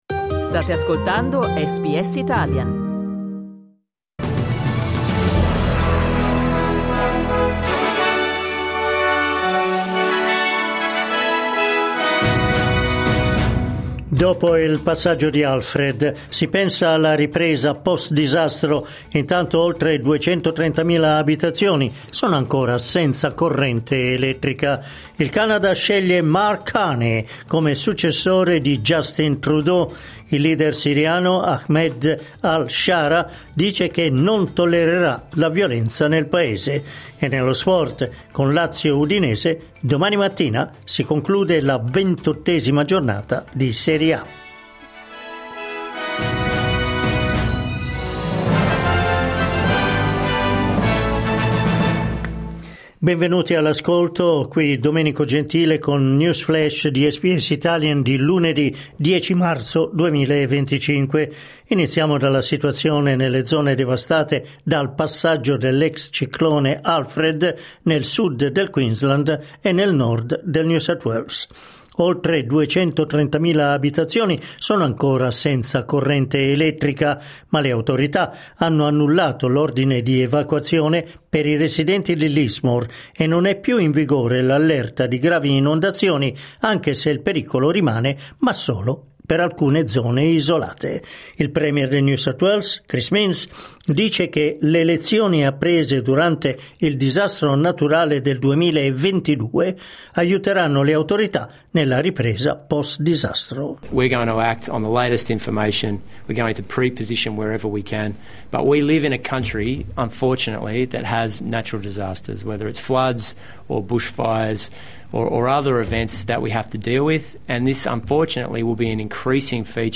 News flash lunedì 10 marzo 2025